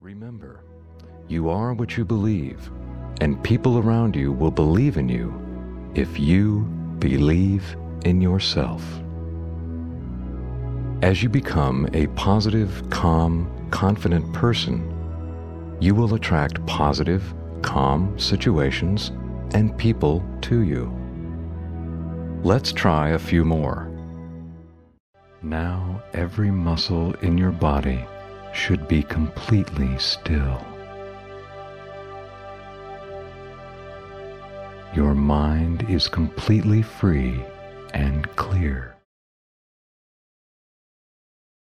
Male
Adult (30-50), Older Sound (50+)
Friendly, smooth, business like, articulate, professional, confident, conversational, calming, authoritative, movie trailer, guy next door, every man, convincing, athletic and a voice that is upbeat and animated when needed to engage the listener.
Relaxation Audio Book
0110Relaxation_Audio_Book.mp3